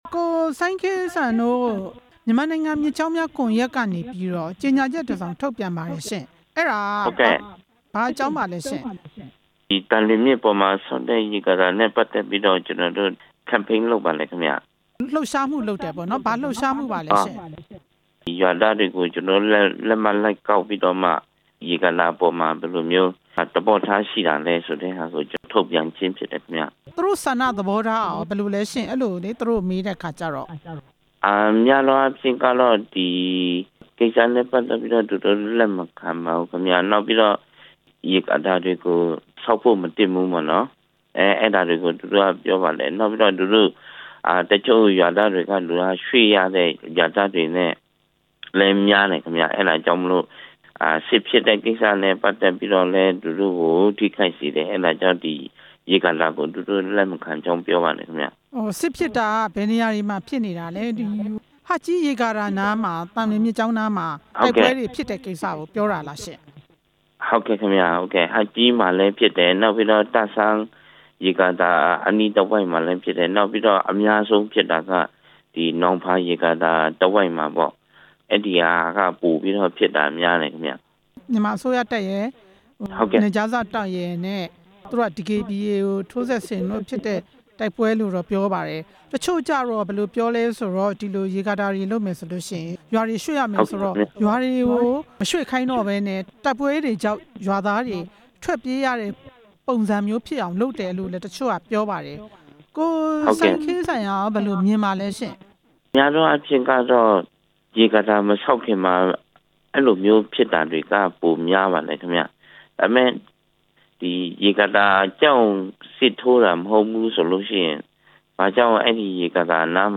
ဆက်သွယ်မေးမြန်းခဲ့ပါတယ်။